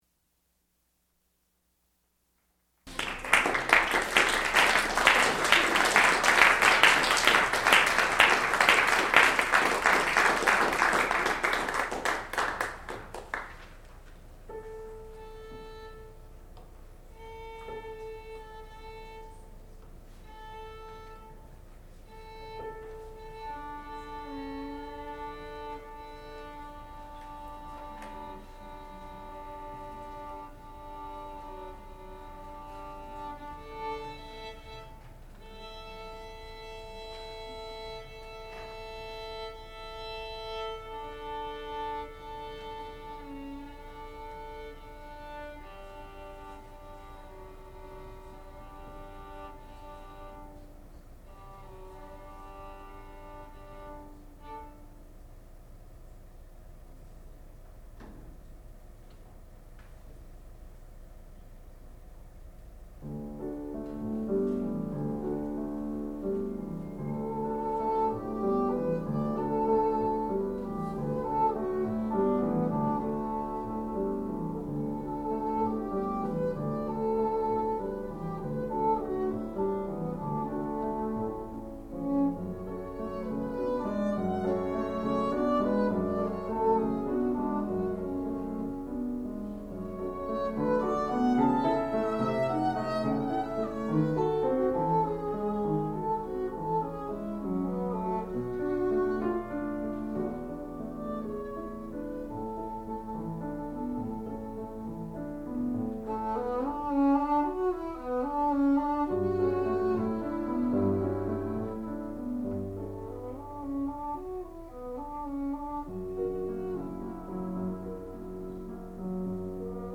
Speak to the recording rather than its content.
Graduate recital